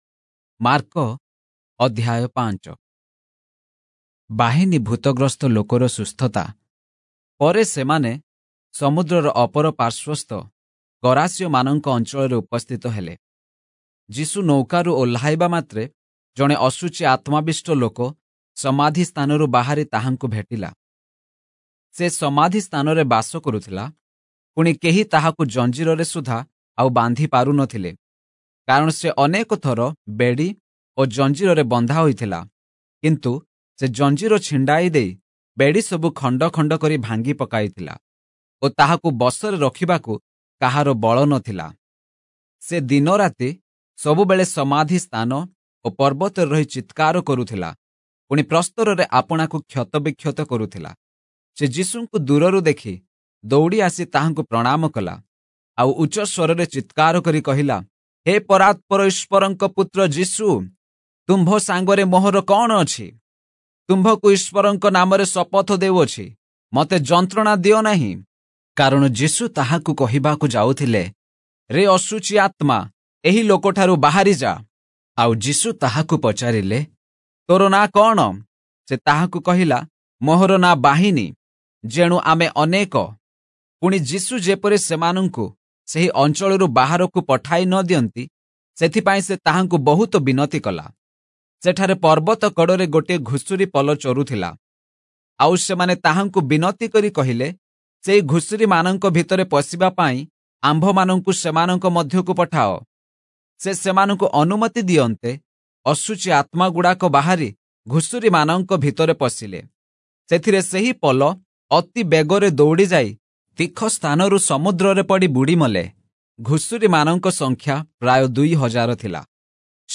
Oriya Audio Bible - Mark 9 in Irvor bible version